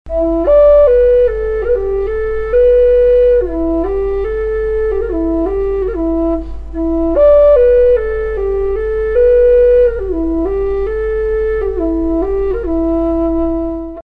Пимак E (падук)
Пимак E (падук) Тональность: E
Яркий, позитивный инструмент с соответствующим звучанием.